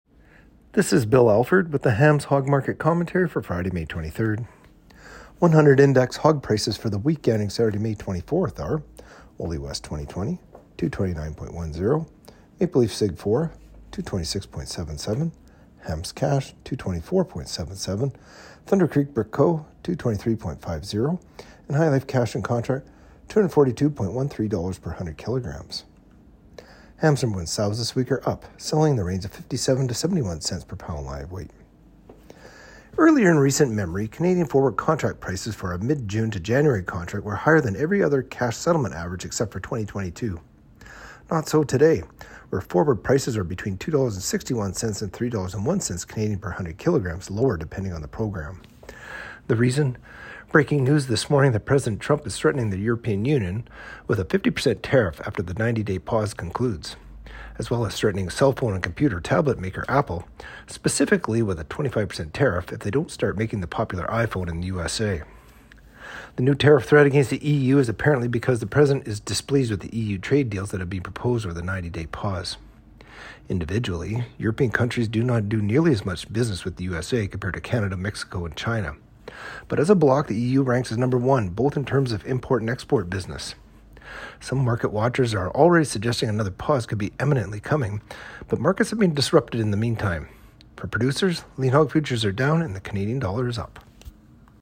Hog-Market-Commentary-May-23-25.mp3